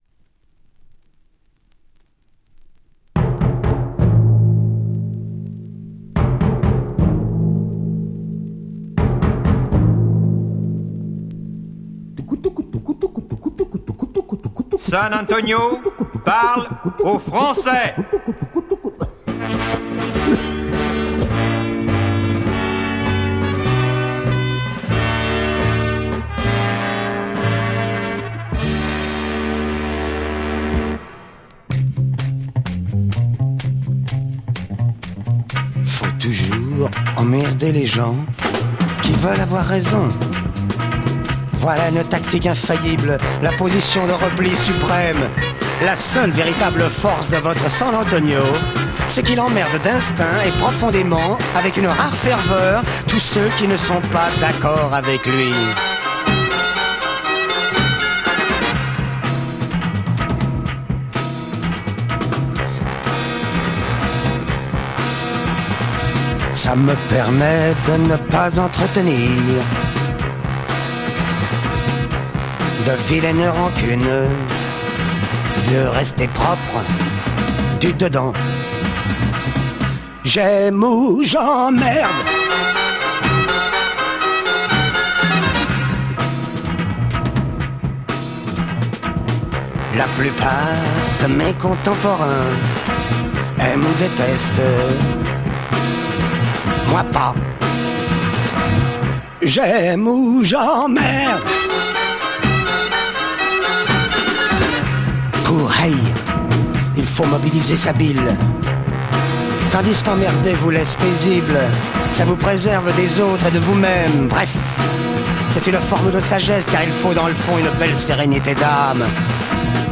Psych funk Spoken words LP